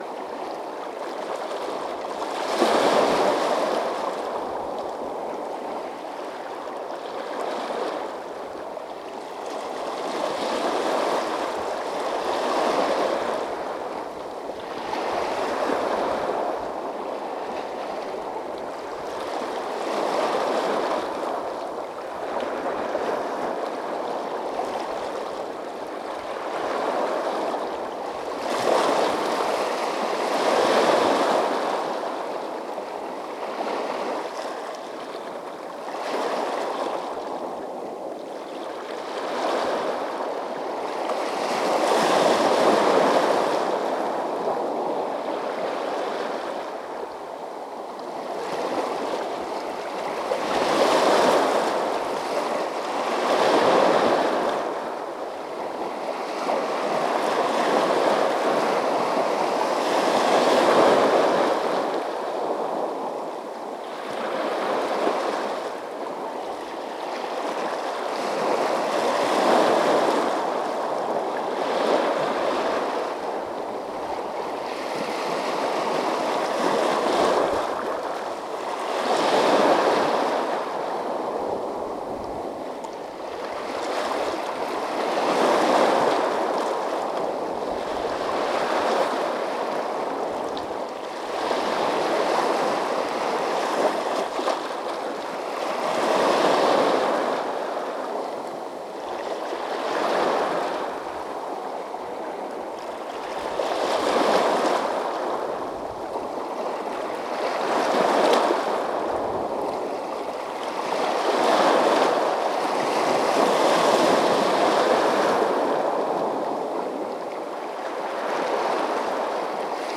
esa-tierra-del-fuego-punta-parana-canal-de-beagle-onashaga.mp3